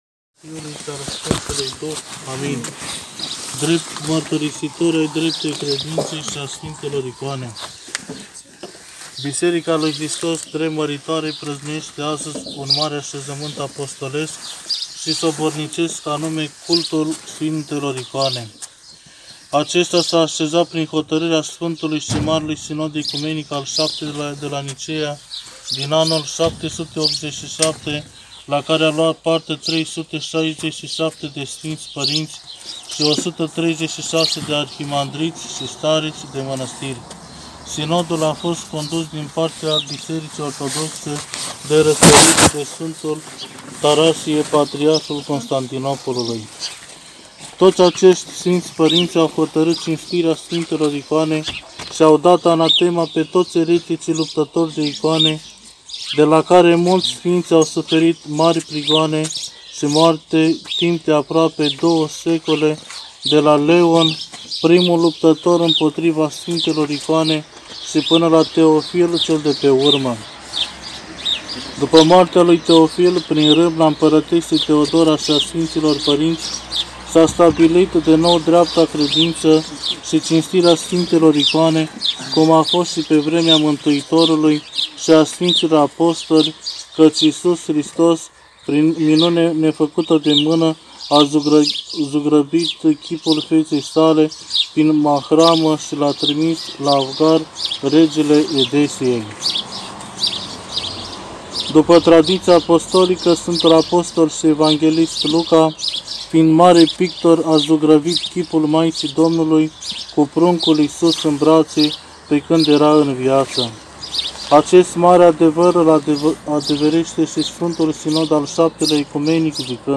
Predica poate fi descărcată în format audio mp3 de aici: